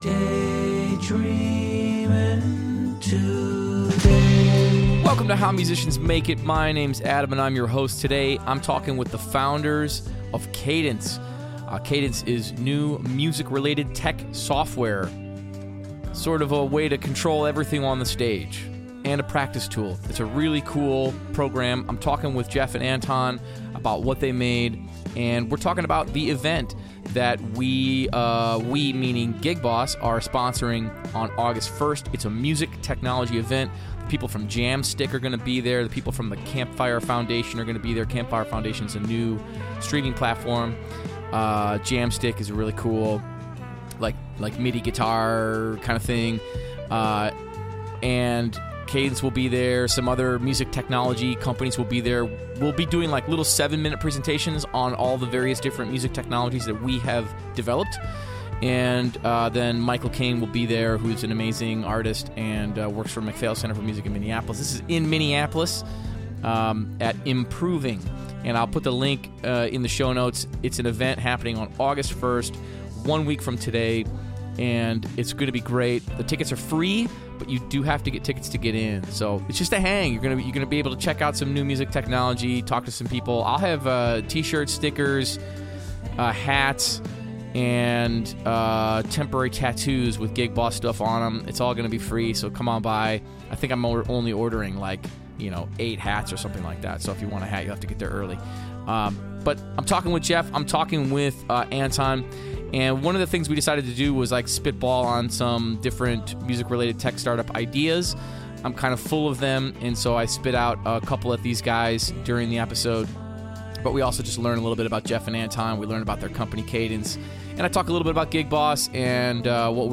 Music Interviews